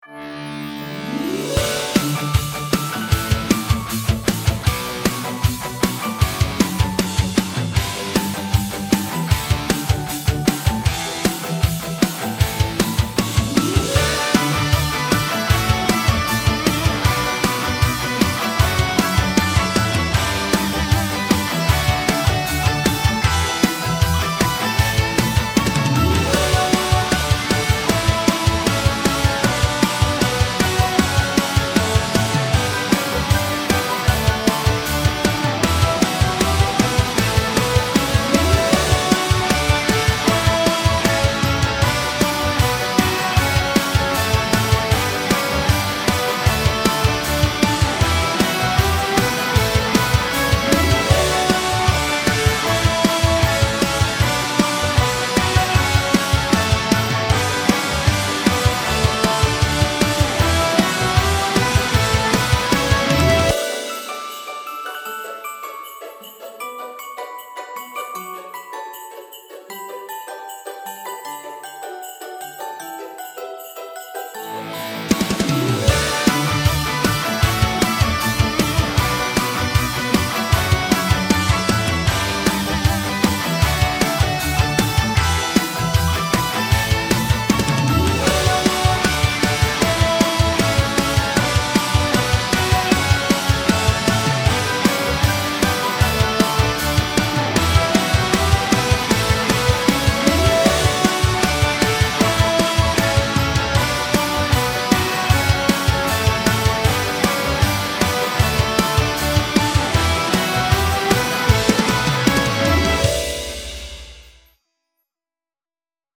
Tag: upbeat